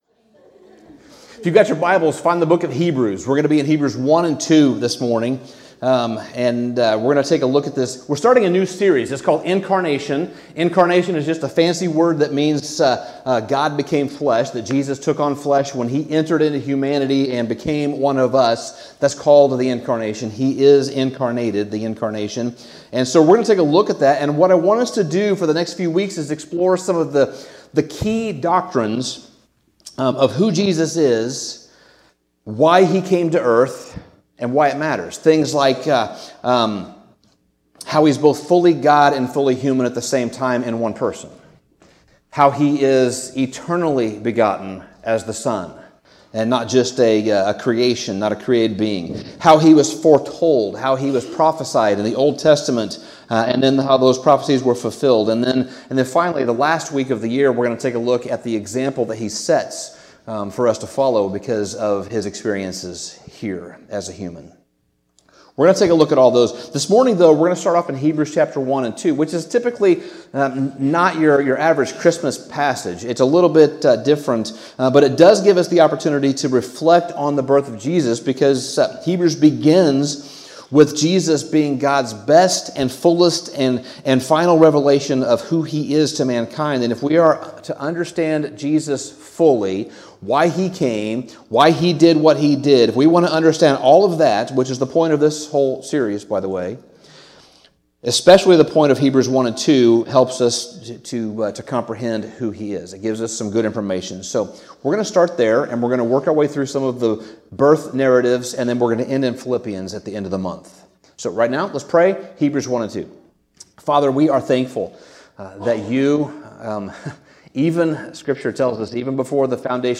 Sermon Summary Hebrews 1 and 2 may not seem like the typical Christmas passage, but the author of this letter provides some incredible insights into the mystery of the Incarnation.